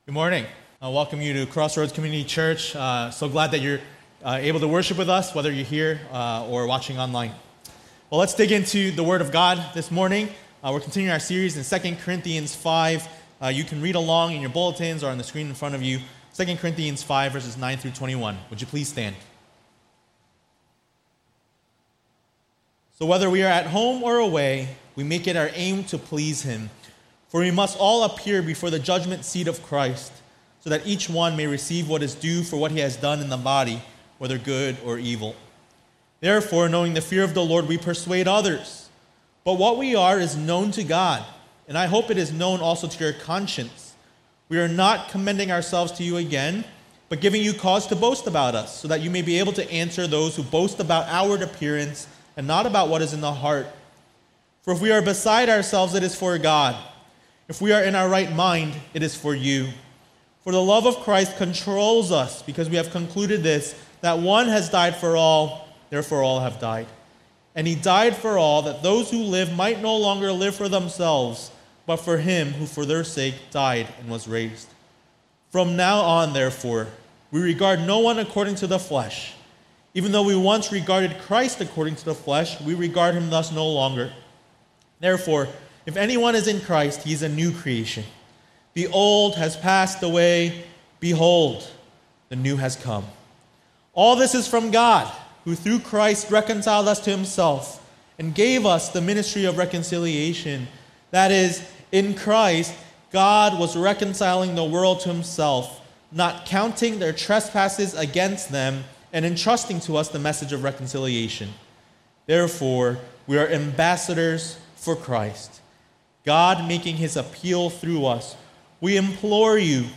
A message from the series "2 Corinthians ."